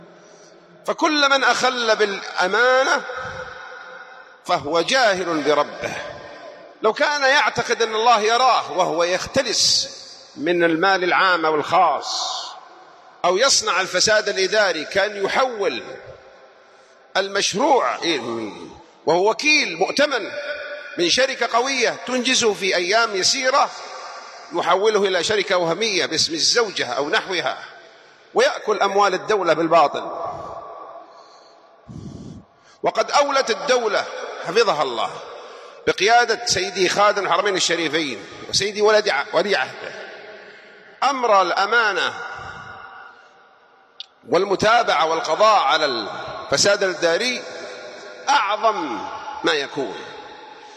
604 [ درر قحطانية ] - التحذير من أكل أموال الدولة بالباطل ( الفساد الإداري ) { خطبة الجمعة } .